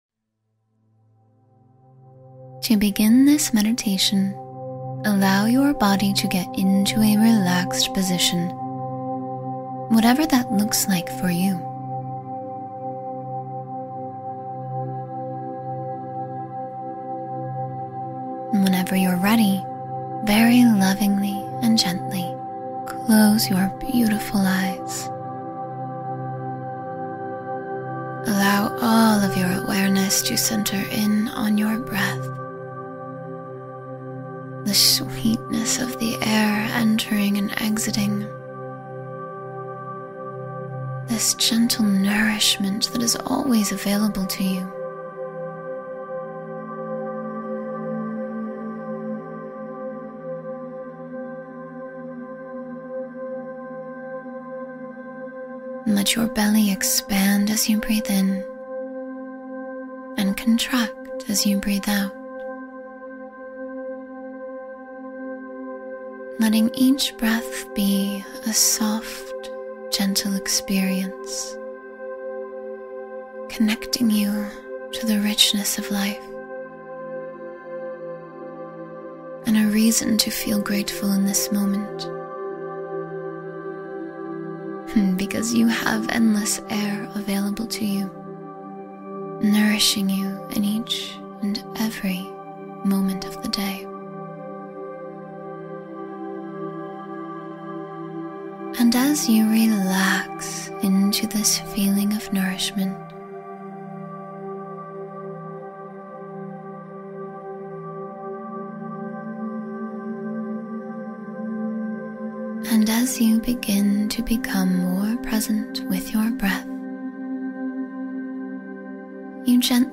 Clear Your Mind and Feel Still — 10-Minute Guided Meditation for Peace